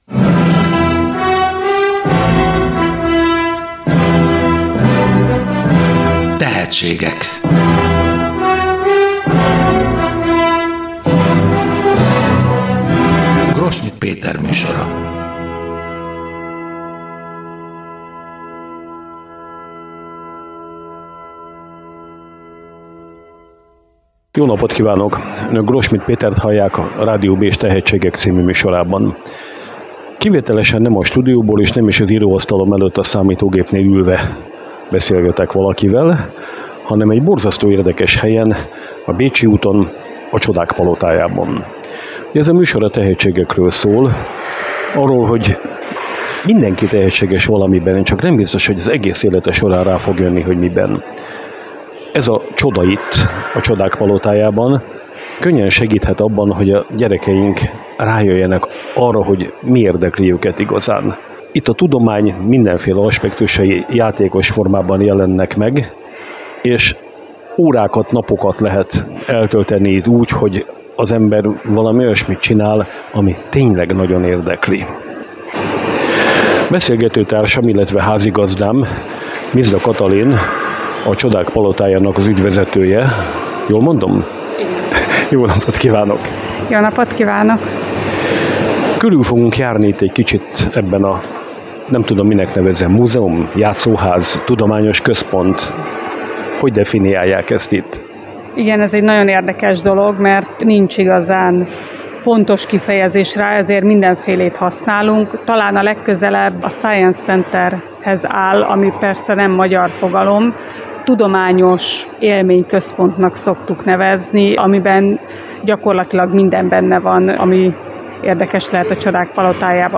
A tematikus egységeinket részletesen bemutató ismeretterjesztő séta a Rádió Bézs oldalán hallgatható vissza .